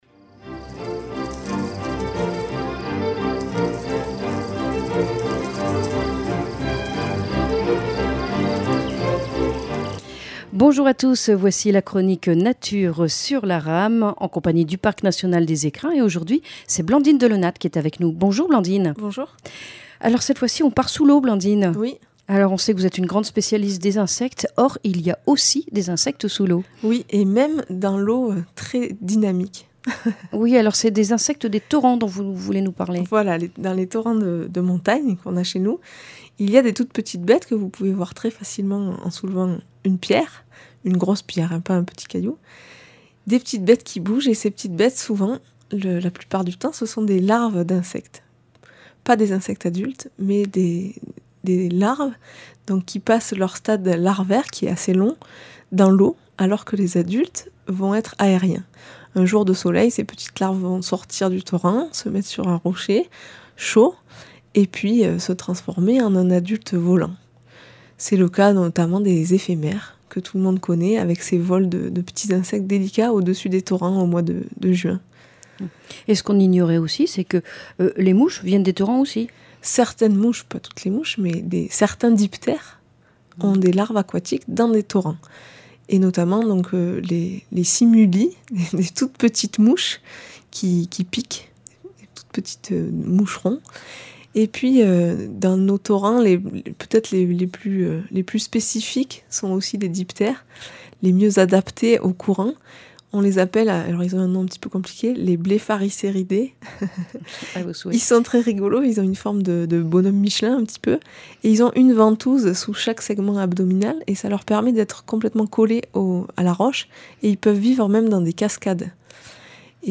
• Chronique nature